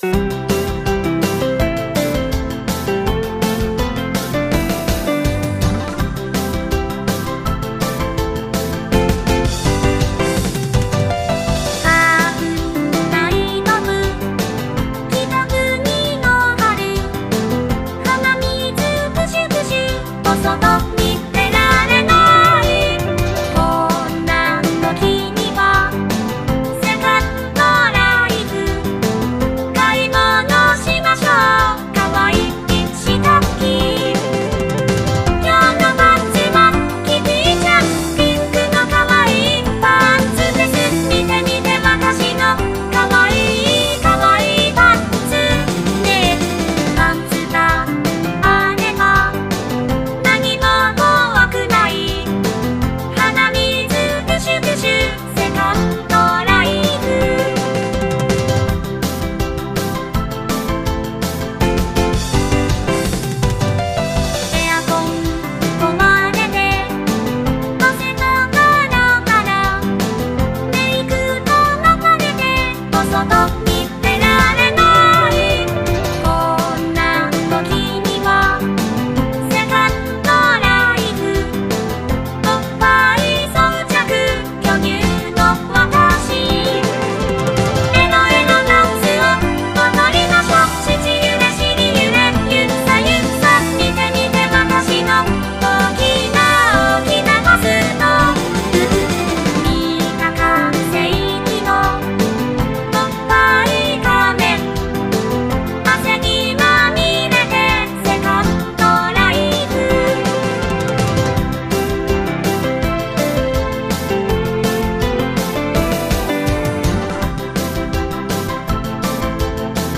サポセンジャー お花見コンサート。
ギター I
ベース
キーボード
ドラムス